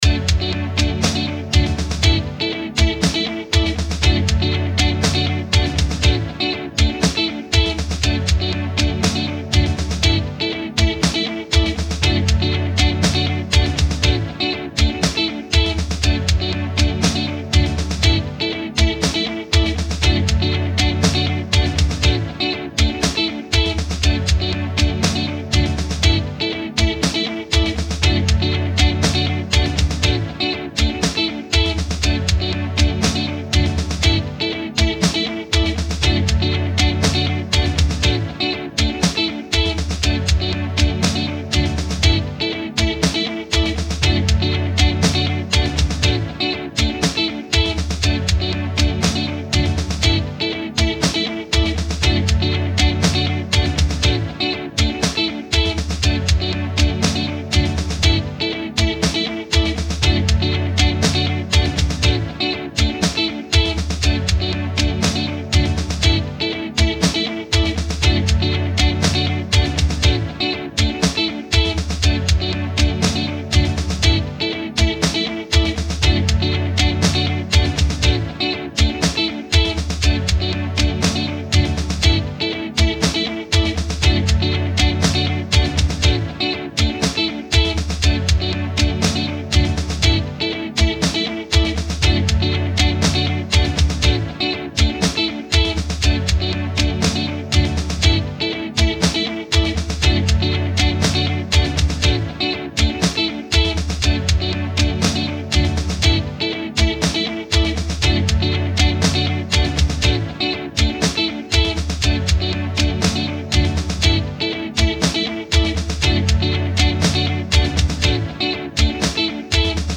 Home > Music > Pop > Bright > Dreamy > Running